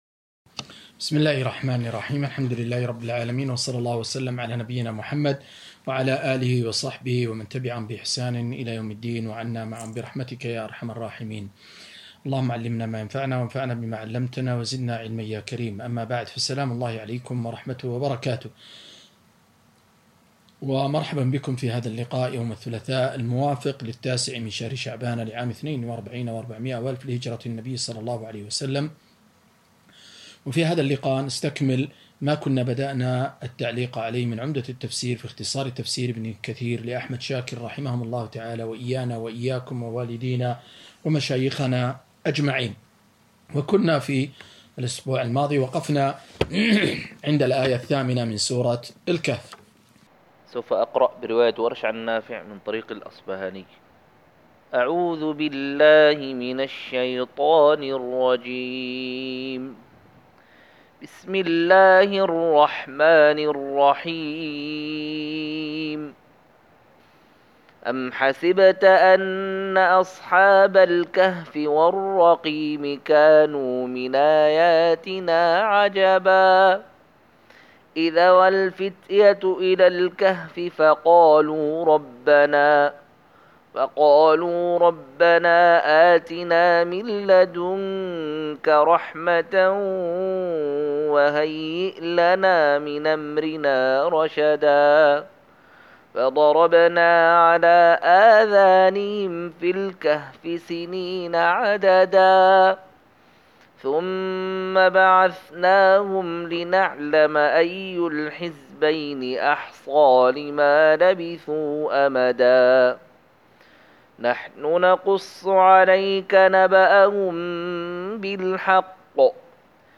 270- عمدة التفسير عن الحافظ ابن كثير رحمه الله للعلامة أحمد شاكر رحمه الله – قراءة وتعليق –